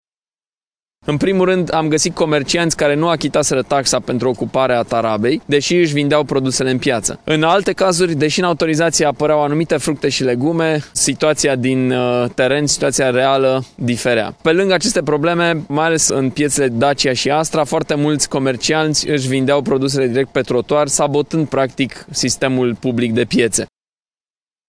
Despre neregulile constatate ne vorbește primarul Allen Coliban: